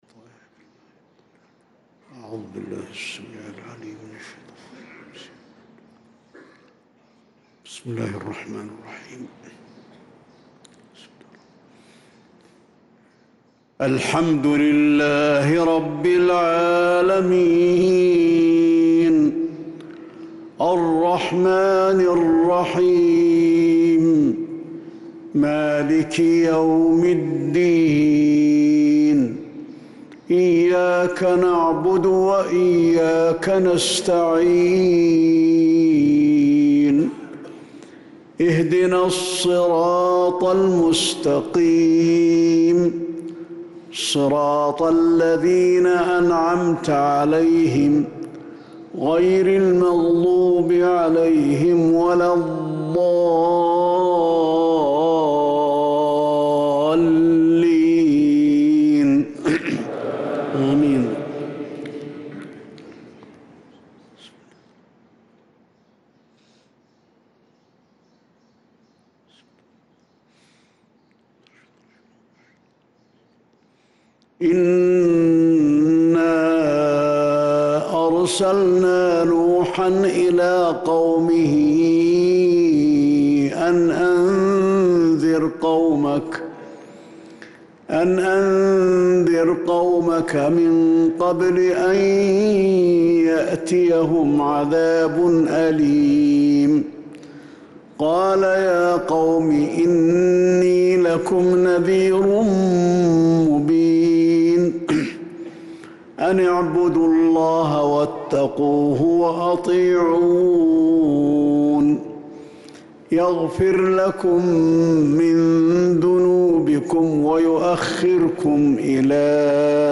صلاة الفجر للقارئ علي الحذيفي 2 شوال 1445 هـ
تِلَاوَات الْحَرَمَيْن .